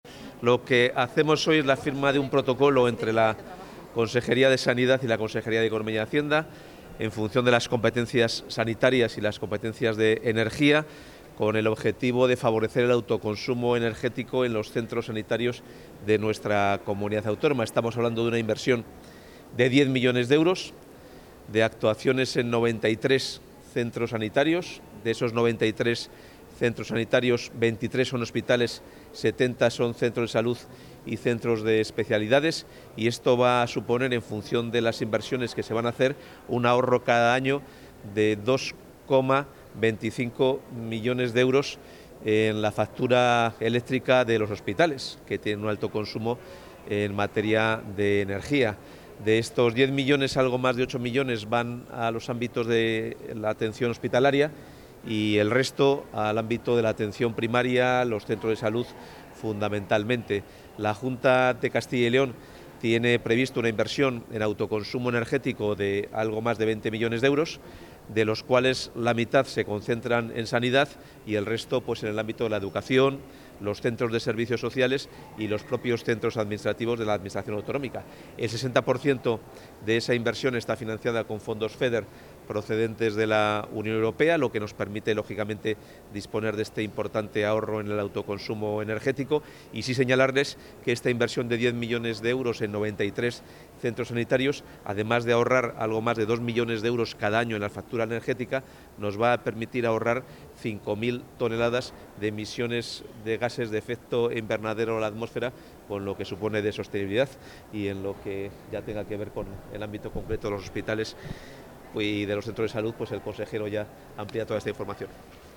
Intervención del consejero de Economía y Hacienda